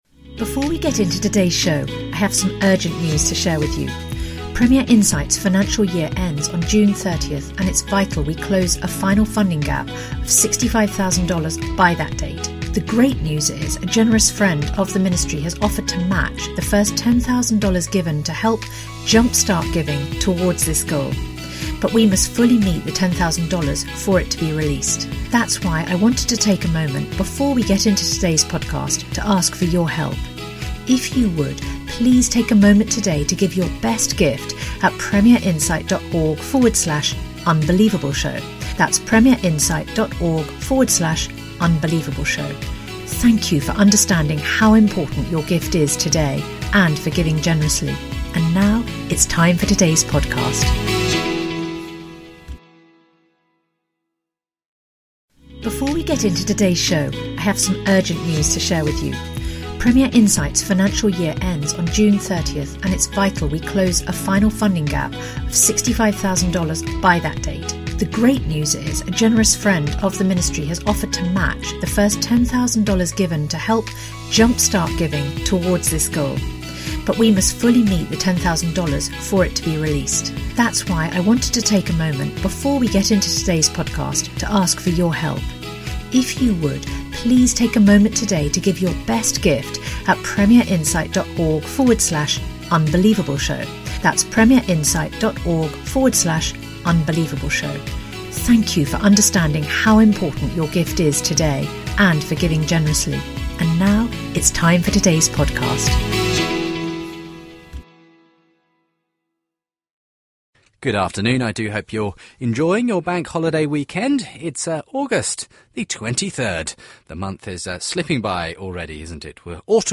Christianity, Religion & Spirituality